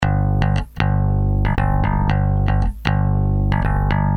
首先我们读取一个叫做Take Your Pick 01的贝司Loop，在Reason Essentials ReFill里有这个loop，如果没有请下载bass_original.mp3文件听听效果。
我们调整个别切片的音高让loop听起来单调平淡一些。
bass_flattened-pitch.mp3